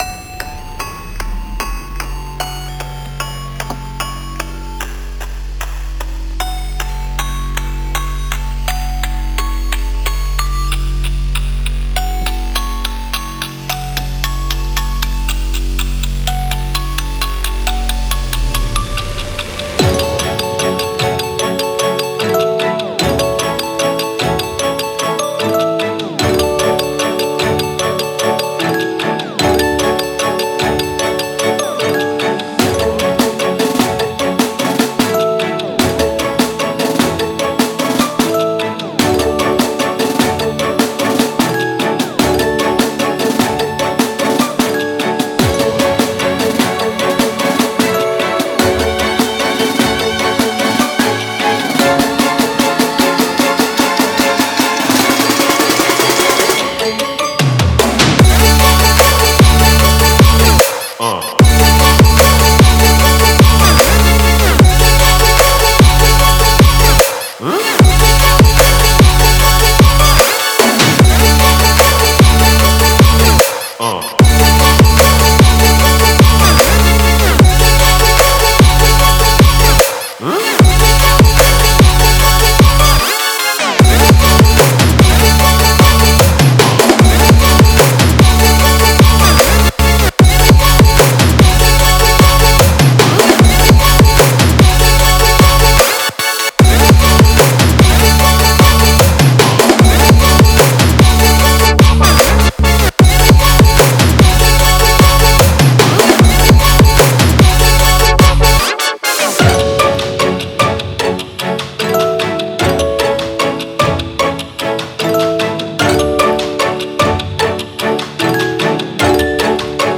Boosted Louder Music With Man Noise
Man Noise repeat A Couple Of Times
A Couple Seconds Of Silence